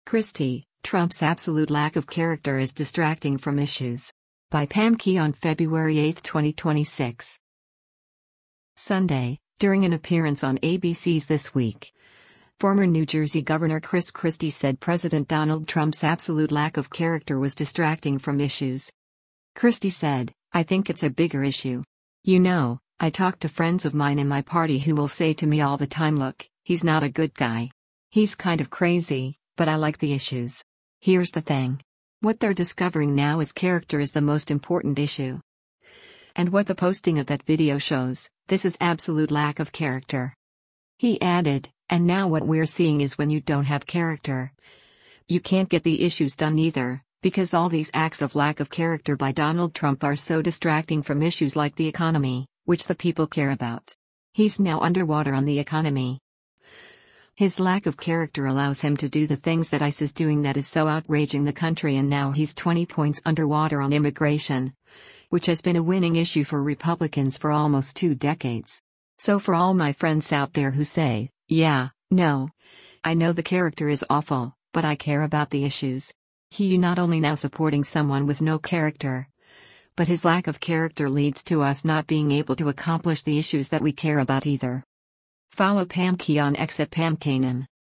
Sunday, during an appearance on ABC’s “This Week,” former New Jersey Gov. Chris Christie said President Donald Trump’s “absolute lack of character” was distracting from issues.